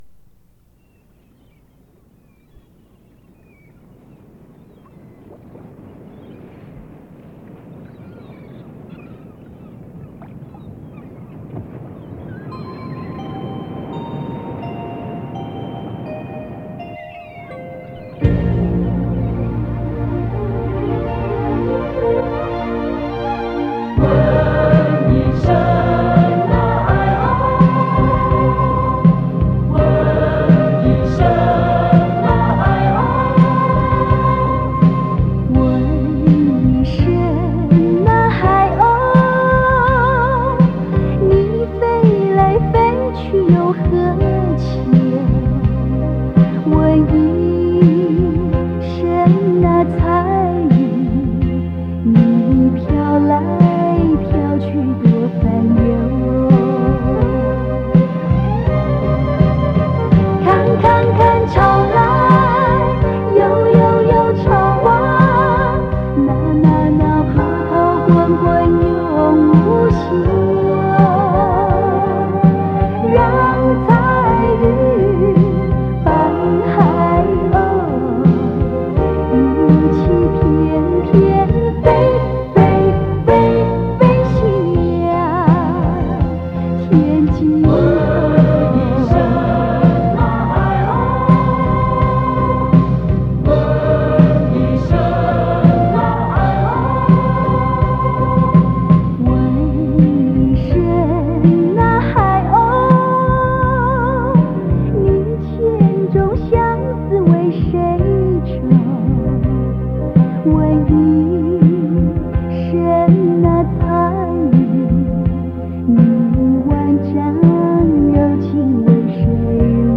磁带数字化：2022-12-24
★影视金曲★